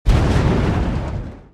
soceress_skill_getupattack.mp3